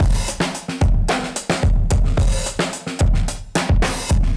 Index of /musicradar/dusty-funk-samples/Beats/110bpm/Alt Sound
DF_BeatB[dustier]_110-04.wav